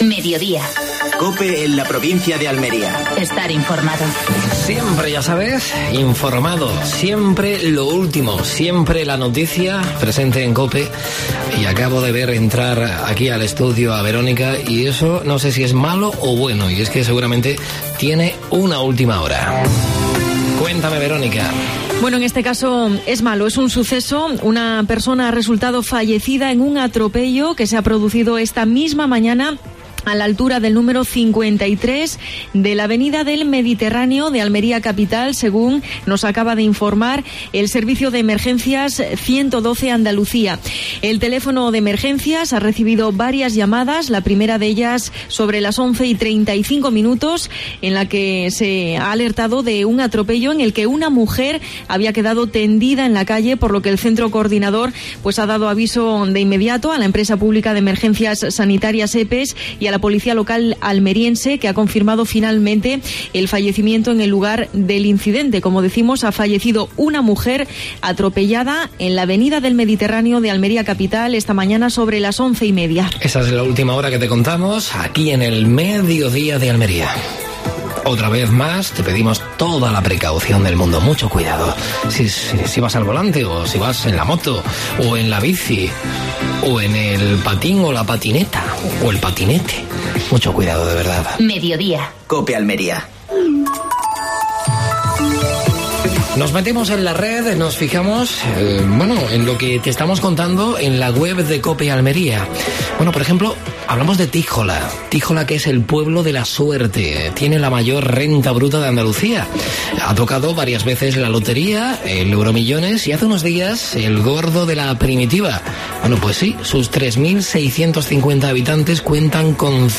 AUDIO: Actualidad en Almería. Entrevista a José Manuel Villegas (cabeza de lista de Ciudadanos al Congreso de los Diputados por Almería).